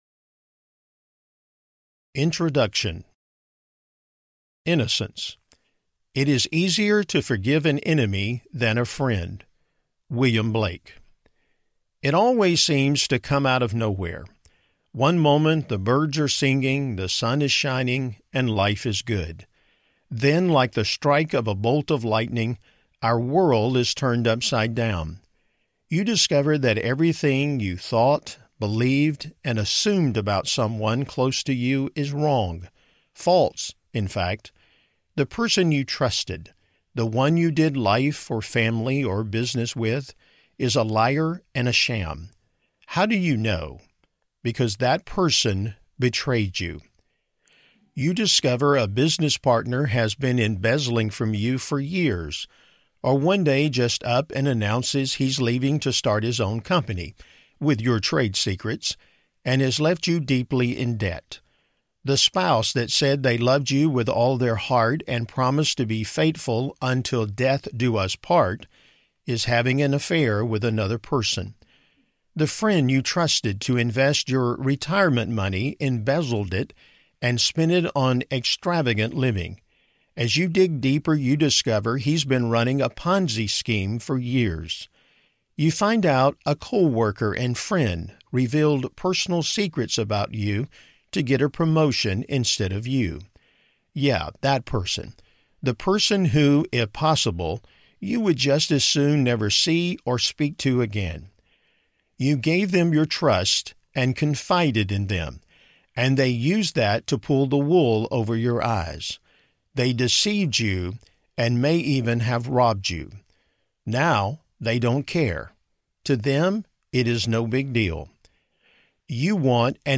Beyond Betrayal Audiobook
5.9 Hrs. – Unabridged